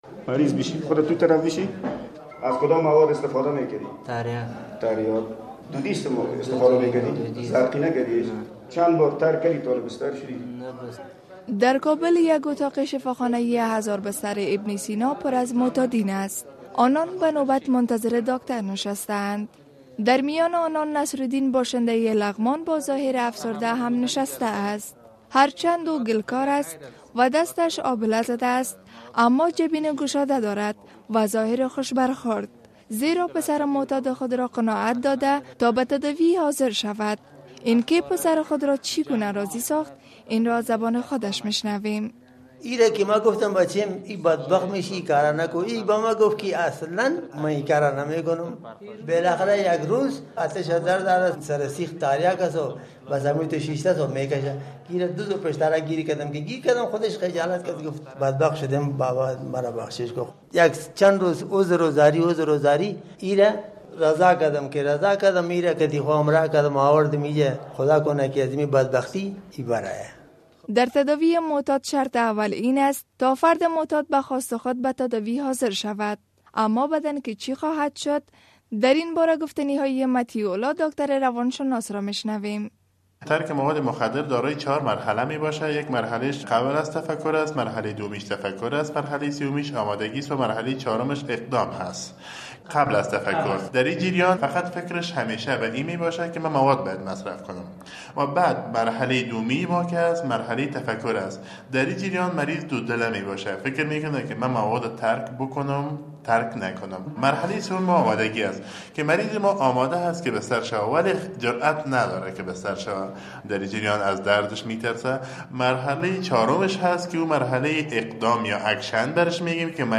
فیچر کاروان زهر